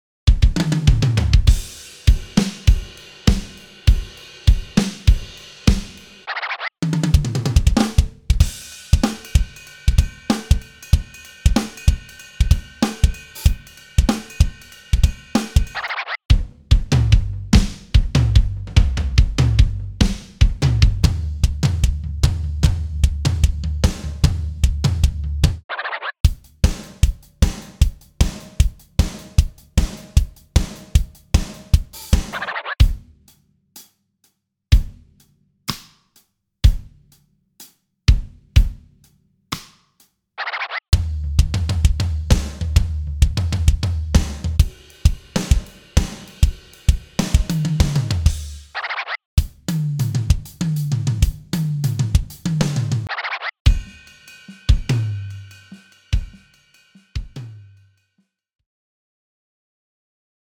165 Unique Gospel Acoustic Live Drum Loops [Grooves and Fills] AND 35 one-shots and hits to drive your creativity and speed up your work flow!
Includes 3 different Kits/Mixes!